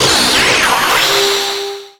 Cri Pokémon (Soleil et Lune)
Cri de Necrozma